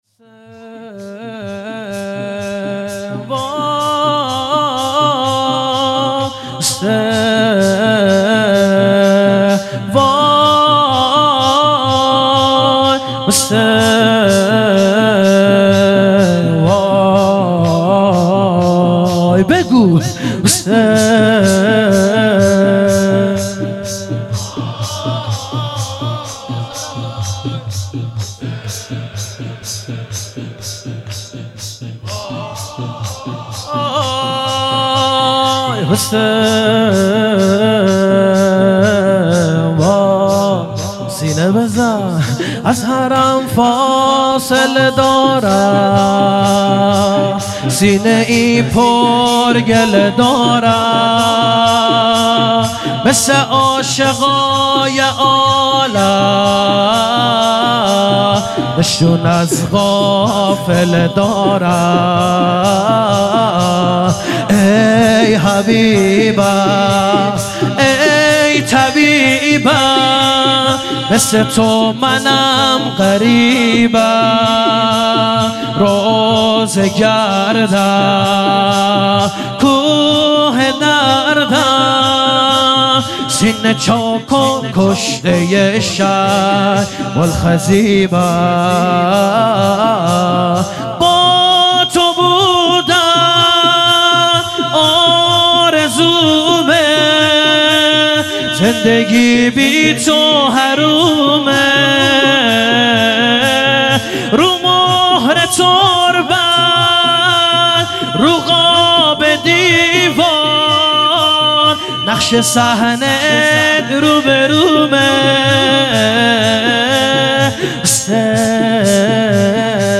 و جلسه ی هفتگی ۲۹ آذر ۱۳۹۷ هیئت حسین جان گرگان
شور